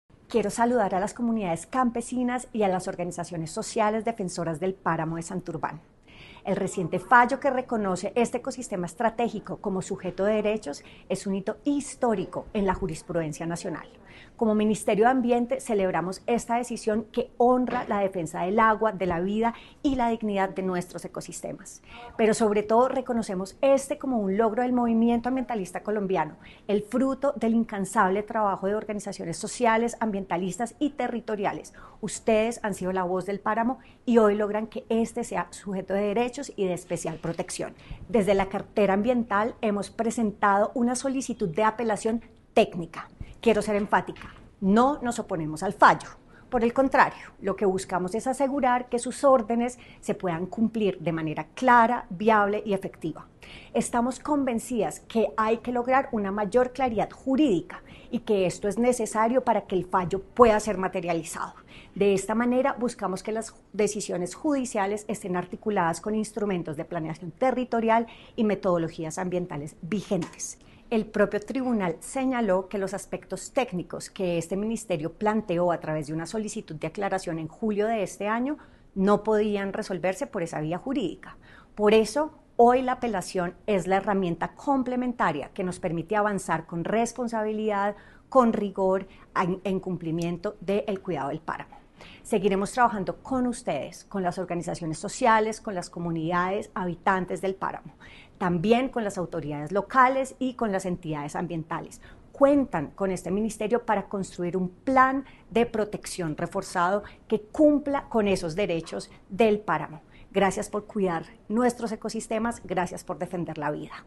Irene Vélez Torres, Ministra (e) de Ambiente y Desarrollo Sostenible